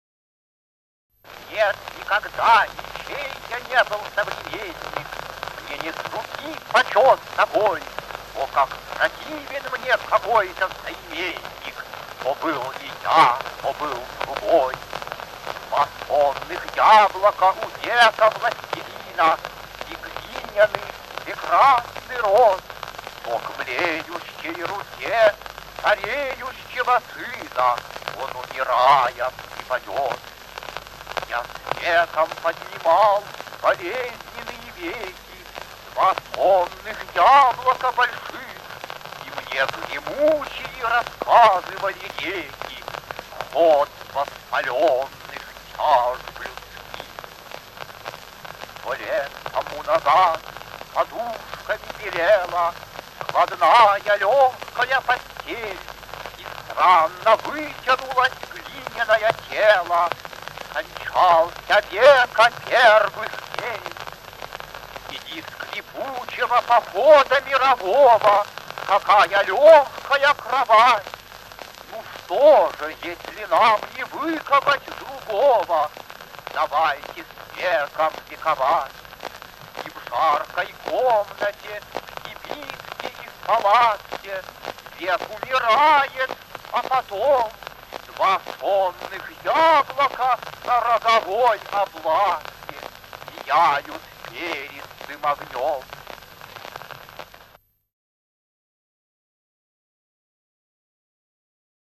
o-e-mandelshtam-chitaet-avtor-net-nikogda-nichej-ya-ne-byl-sovremennik